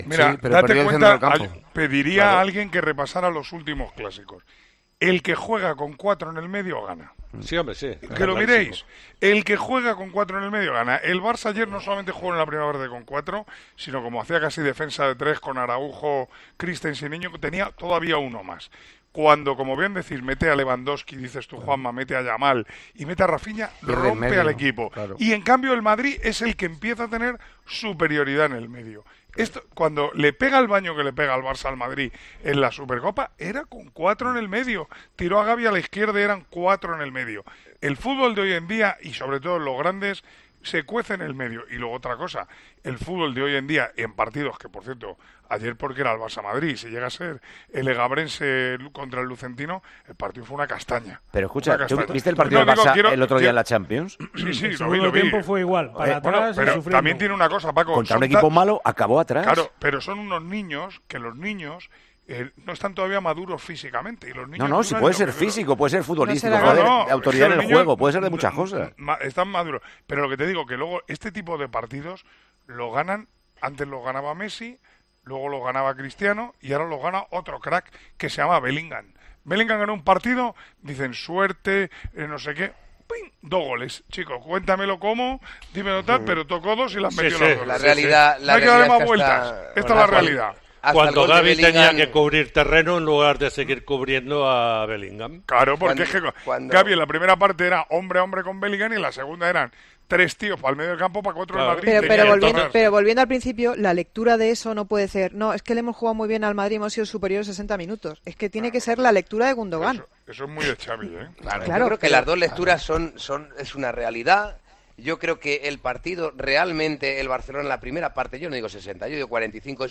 Manolo Lama hizo su particular análisis del Clásico del pasado sábado durante el Tertulión de los Domingos de Tiempo de Juego, con Juanma Castaño: "El que lo hace, gana".
ESCUCHA EL ANÁLISIS DE MANOLO LAMA SOBRE EL CLÁSICO, EN EL TERTULIÓN DE LOS DOMINGOS DE TIEMPO DE JUEGO